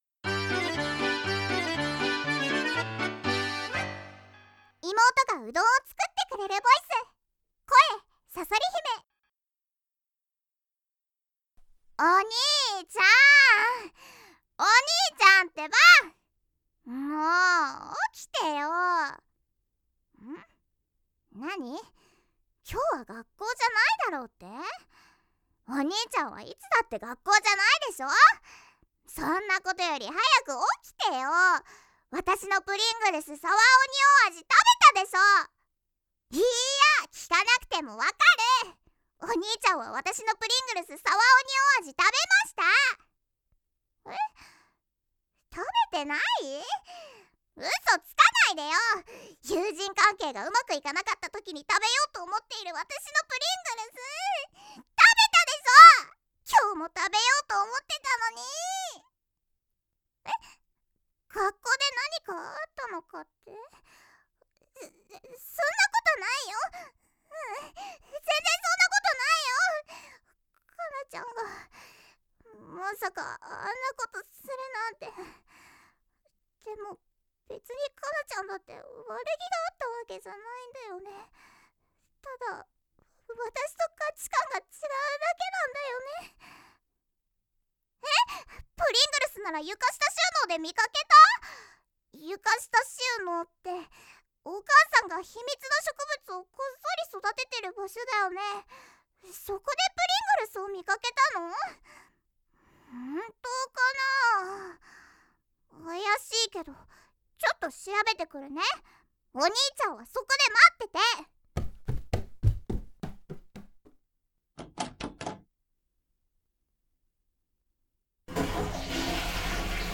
【繁体中文版】妹がうどんを作ってくれるボイス
妹妹做烏冬麵的音聲.mp3